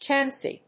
/tʃˈænsi(米国英語), ˈtʃænsi:(英国英語)/